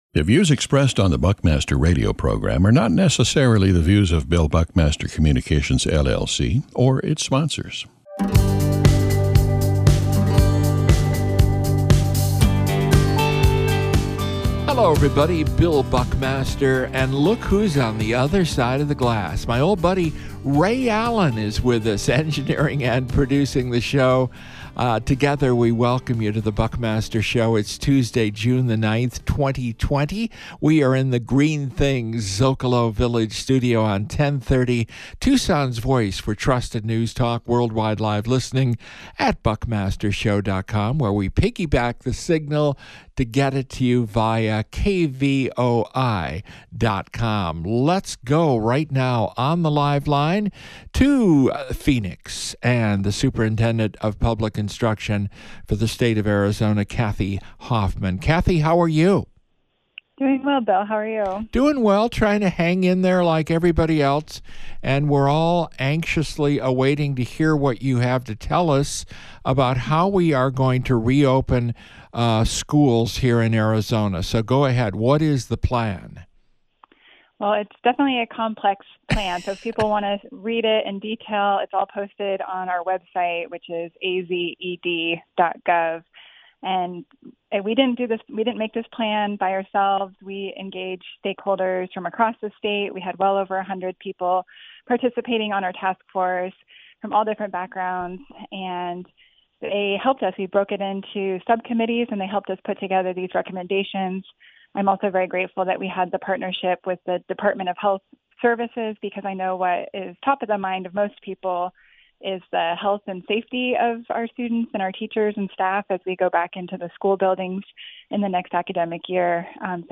We begin the program with an update on Arizona’s plan to reopen all public schools this fall. We talk with Arizona Superintendent of Public Instruction Kathy Hoffman. Then the stock market has made a remarkable three month recovery.
Also, our “2020 Your Vote, Your Voice” candidate interview series continues with Democrat Adelita Grijalva, Pima County District 5 Supervisorial hopeful. Plus, when is Tucson’s arts scene going to get cranked up again?